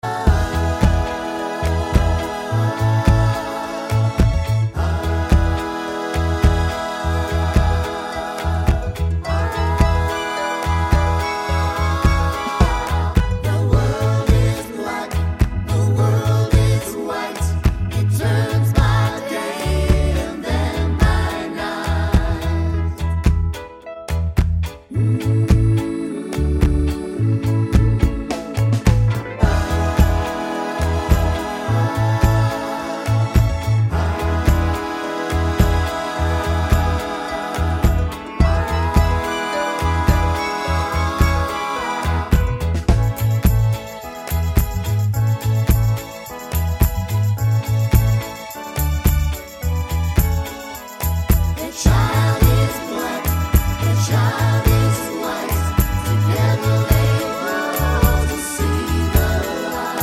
no Backing Vocals Reggae 2:58 Buy £1.50